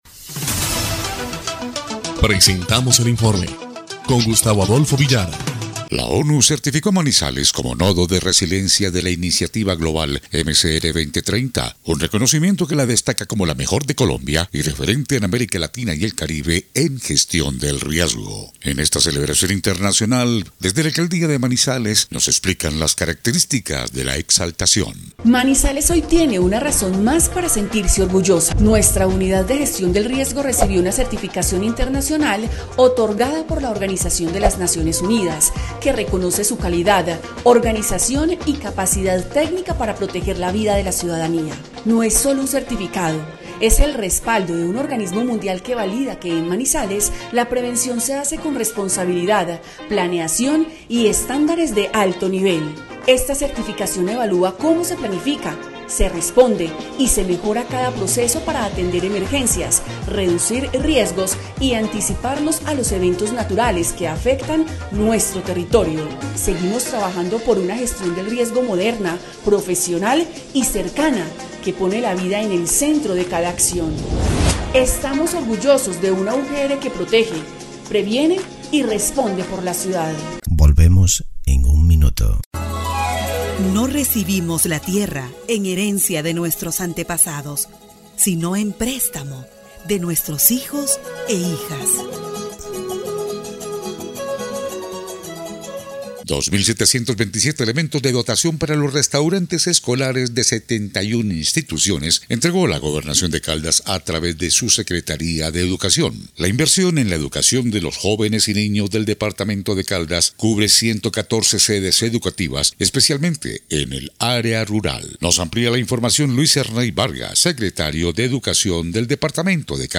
EL INFORME 2° Clip de Noticias del 6 de febrero de 2026